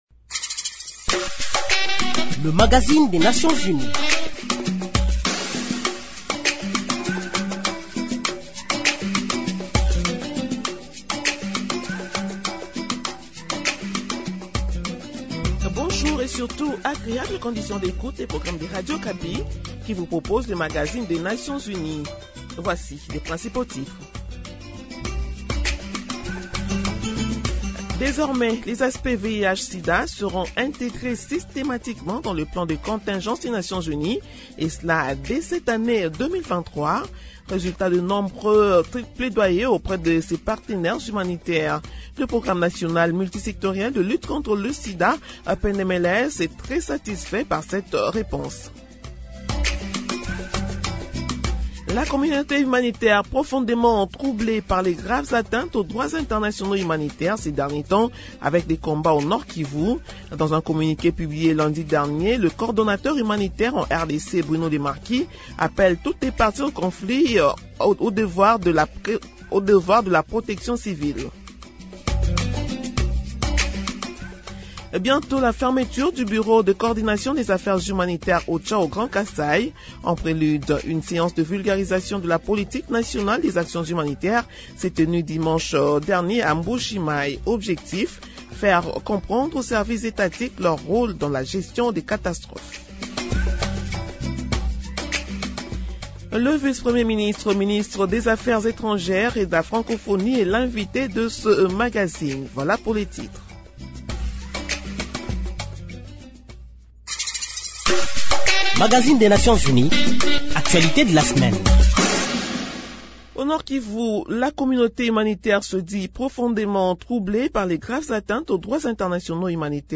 Nouvelles en bref Le plan minimum absolu du Programme alimentaire mondial (PAM) pour 1,5 million de personnes les plus vulnérables est confronté à un besoin de financement net de 381,2 millions de dollars américains pour les six prochains mois.